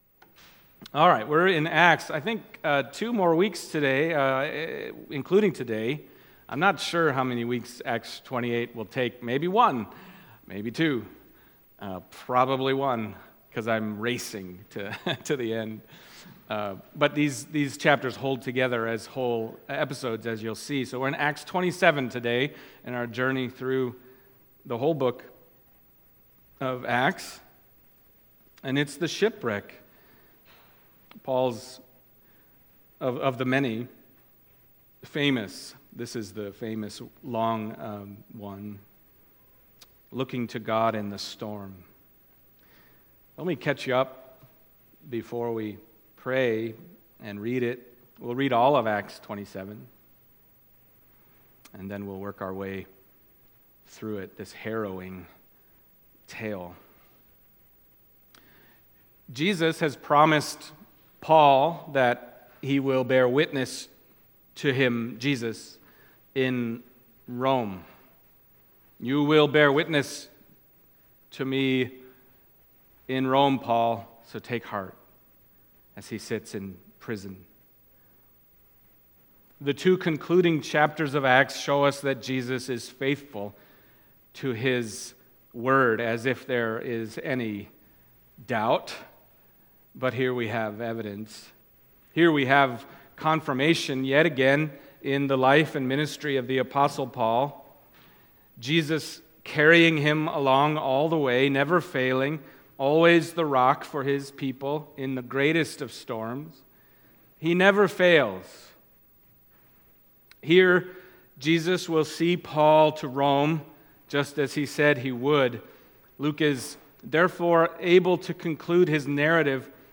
Acts Passage: Acts 27:1-44 Service Type: Sunday Morning Acts 27 « What Is Believer’s Baptism?